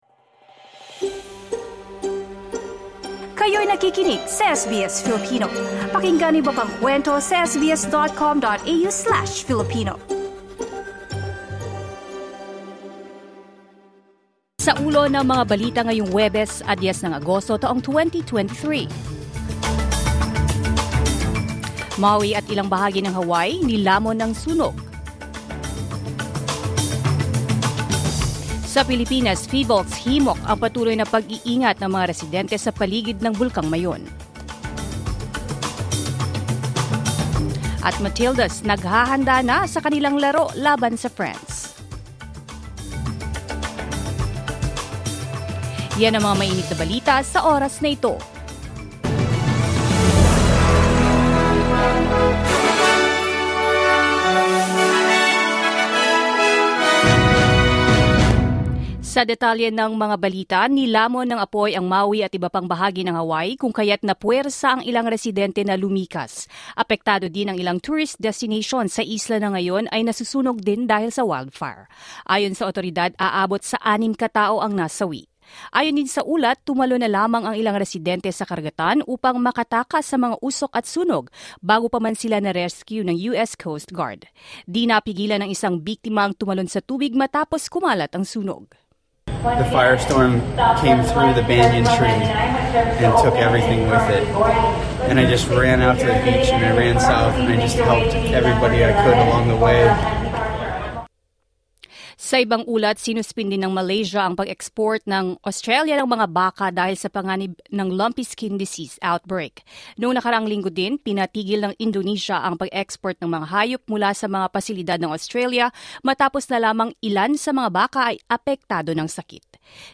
SBS News in Filipino, Thursday 10 August 2023